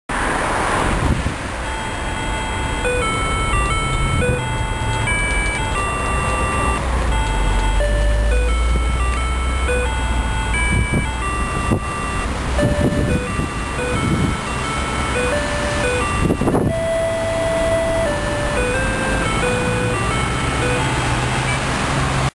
福岡県では結構レアな小糸□号のメロディーです。